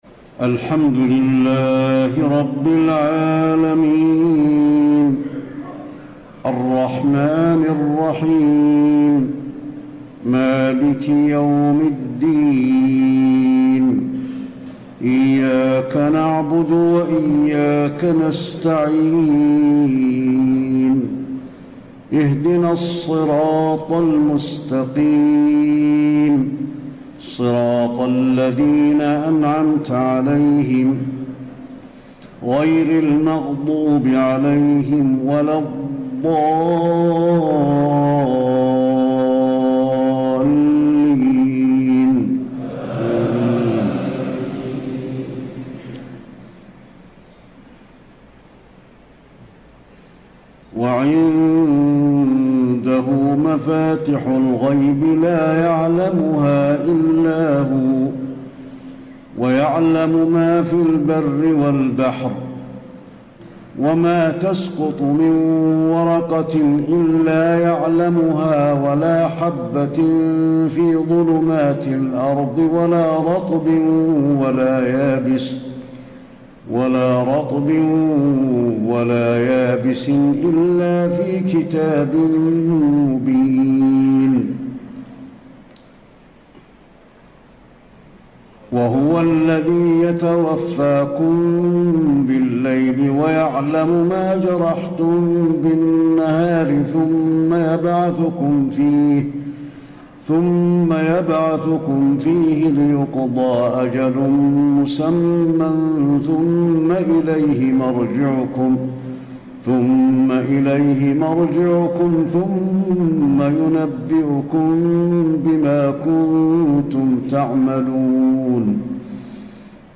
تهجد ليلة 27 رمضان 1434هـ من سورة الأنعام (59-110) Tahajjud 27 st night Ramadan 1434H from Surah Al-An’aam > تراويح الحرم النبوي عام 1434 🕌 > التراويح - تلاوات الحرمين